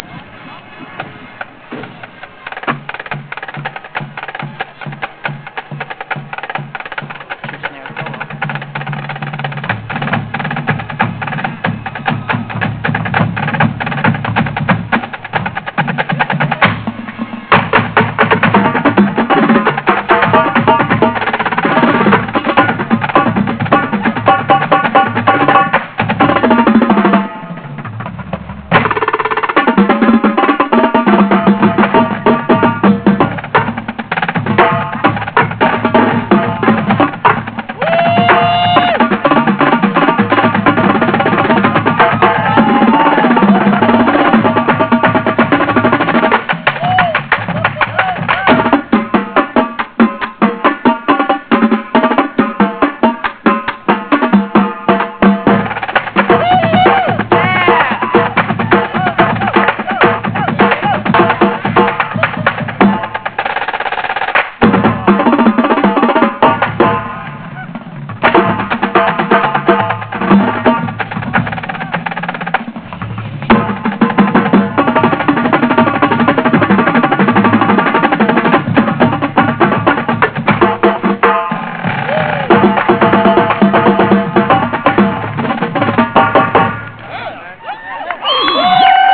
cadence.wav